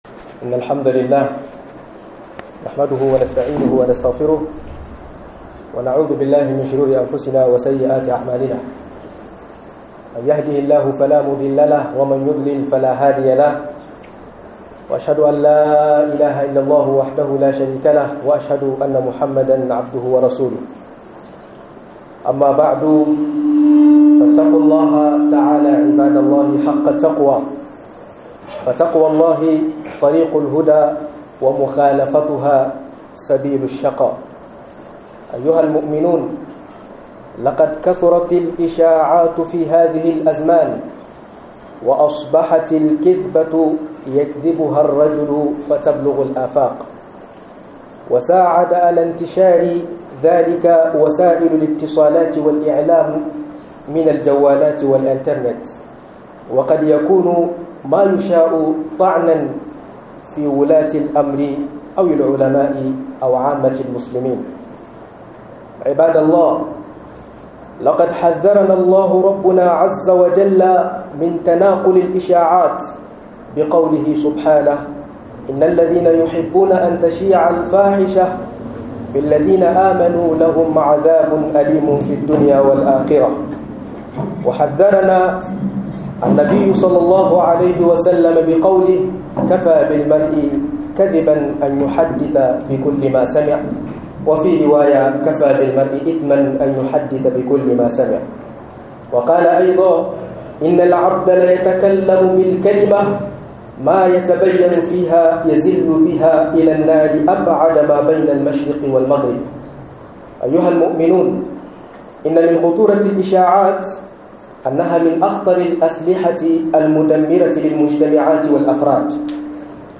Jan kunnay game da yada labaran karya - MUHADARA